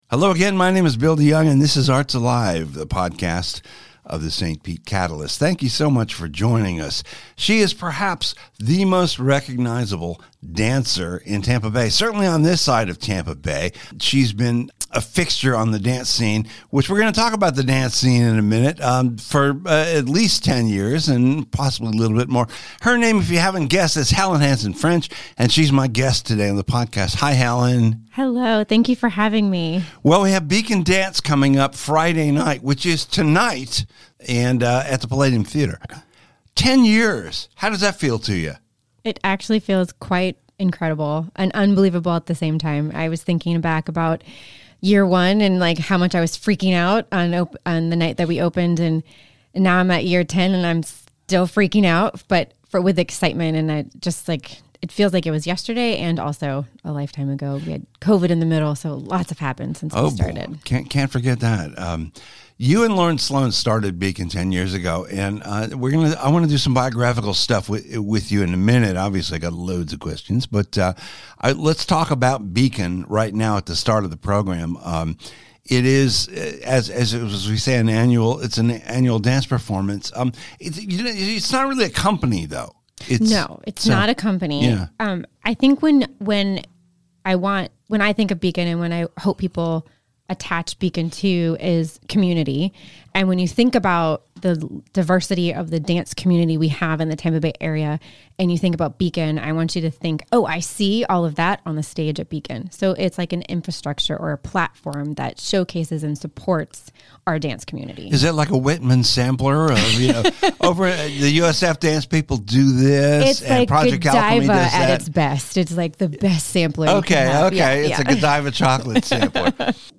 In advance of tonight’s 10th anniversary concert, she sat down for an Arts Alive! podcast interview.